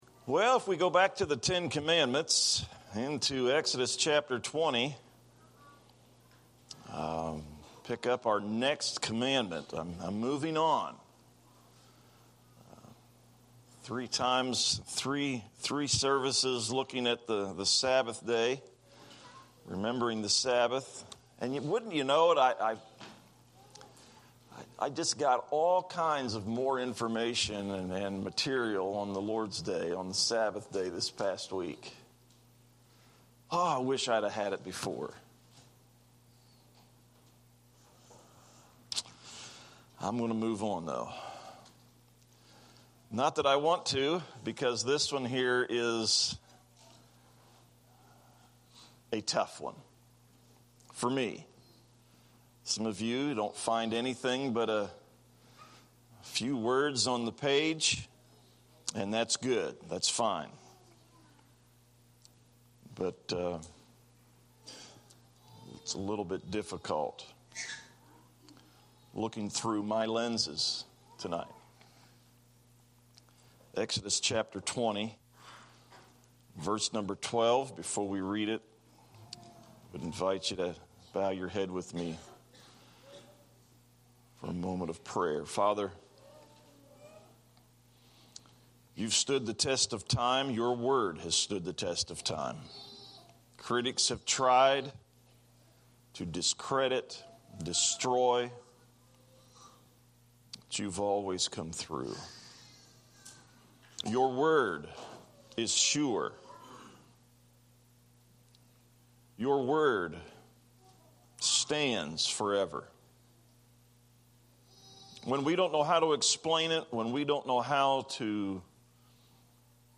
A sermong